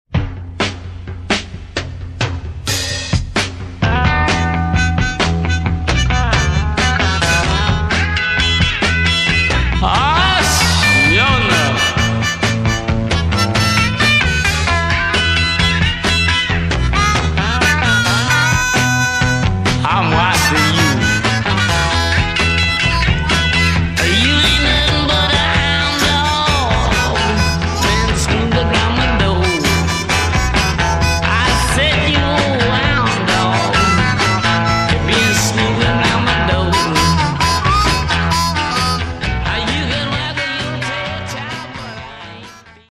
SWAMP ROCK